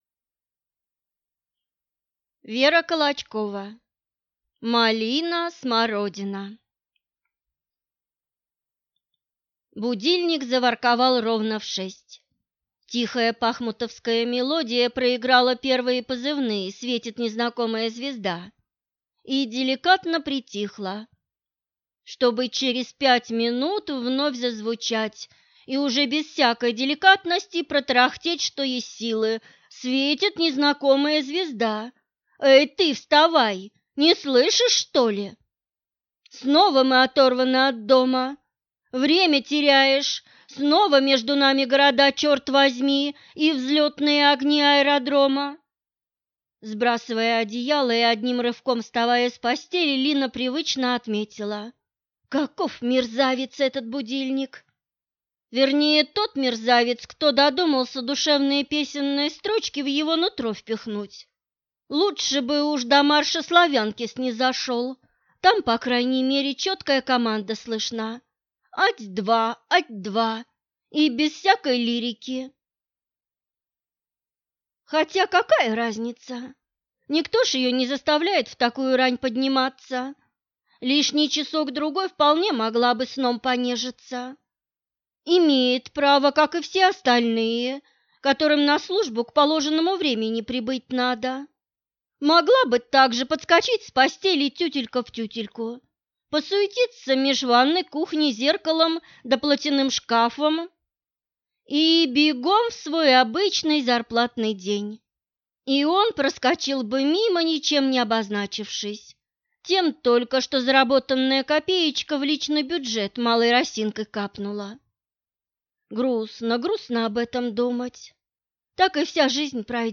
Аудиокнига Малина Смородина | Библиотека аудиокниг